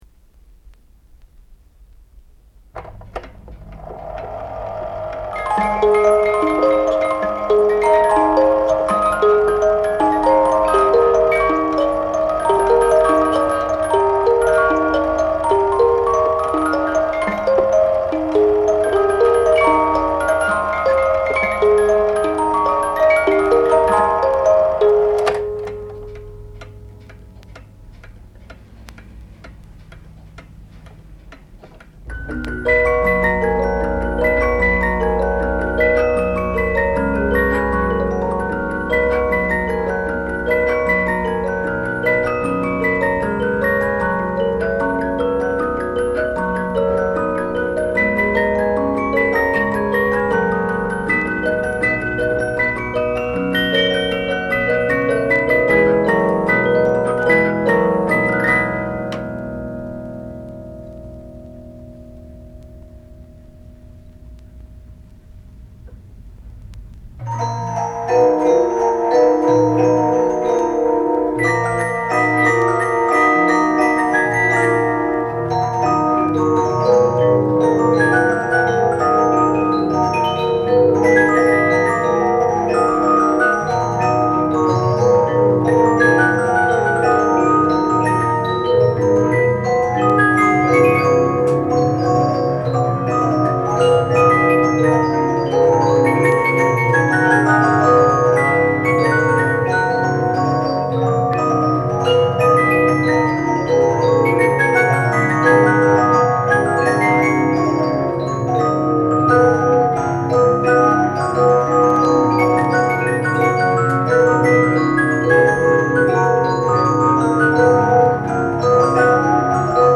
Music_Boxes02.mp3